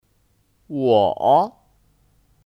我 Wǒ (Kata ganti orang): Saya